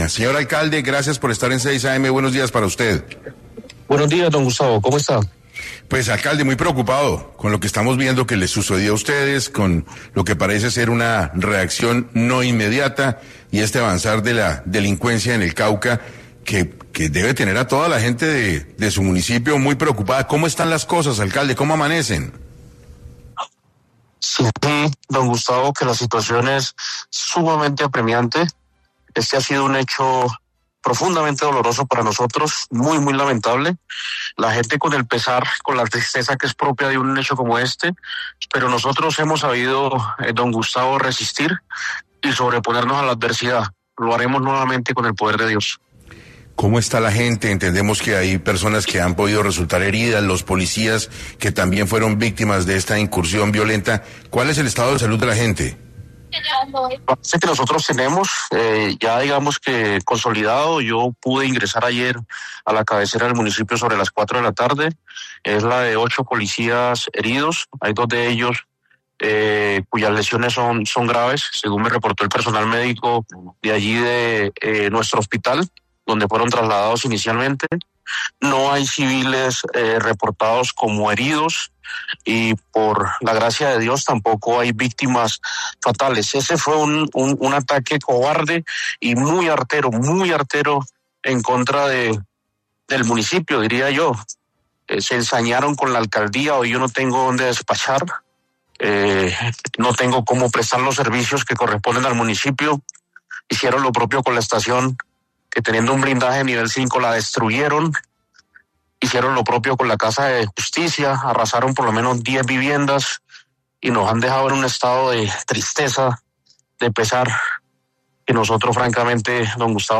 El alcalde detalló en 6AM la situación de los ocho policías heridos tras hostigamientos en Buenos Aires, Cauca
Un día después del ataque, el alcalde de Buenos Aires, Cauca, Pablo césar Peña, dio un balance en 6AM de lo que sucedió en el municipio.